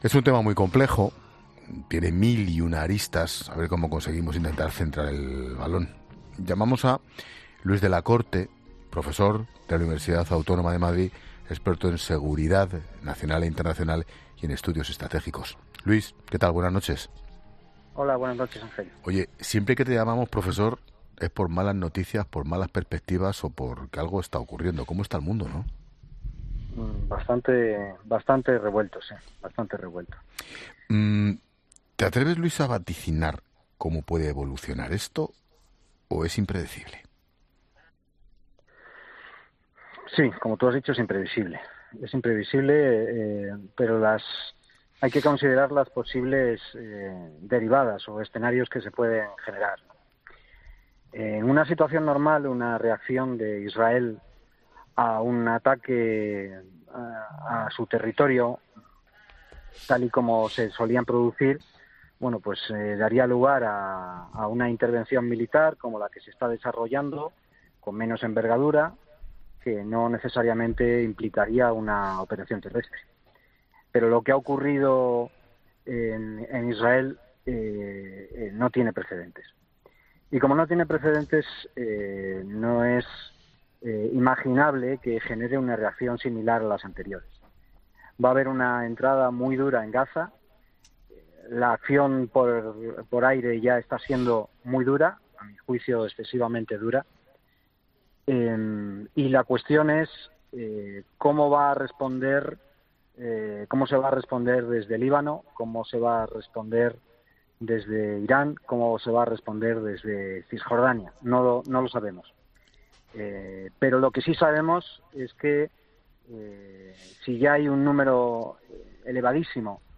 Ángel Expósito analiza en La Linterna con el experto en estudios estratégicos si Hamás es un peón de Irán y cuál es el riesgo futuro para España y la UE